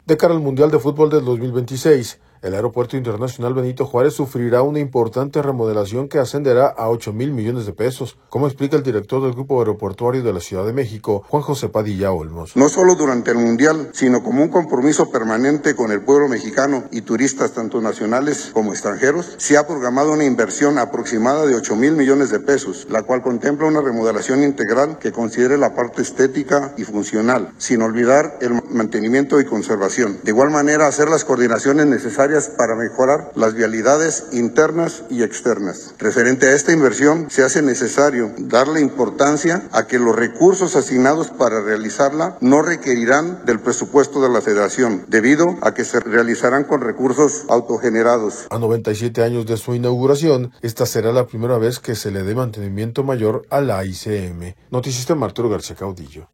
De cara al mundial de futbol del 2026, el Aeropuerto Internacional Benito Juárez sufrirá una importante remodelación que ascenderá a ocho mil millones de pesos, como explica el director del Grupo Aeroportuario de la Ciudad de México, Juan José Padilla Olmos.